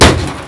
impact_4.wav